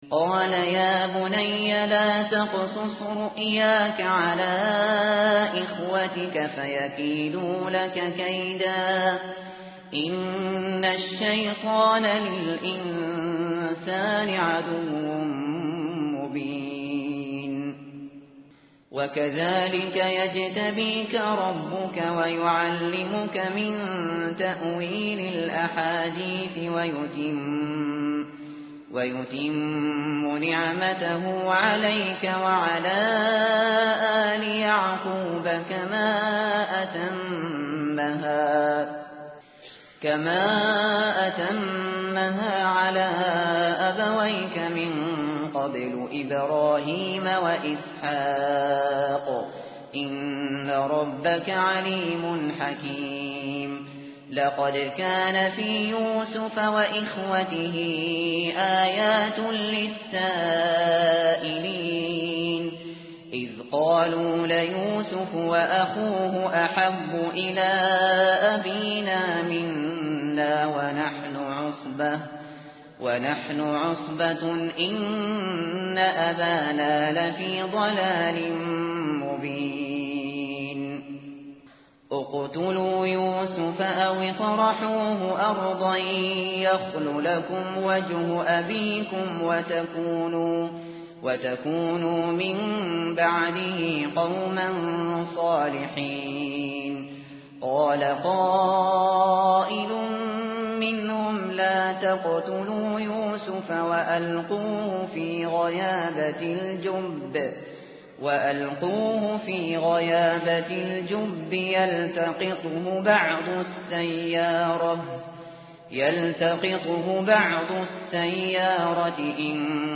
متن قرآن همراه باتلاوت قرآن و ترجمه
tartil_shateri_page_236.mp3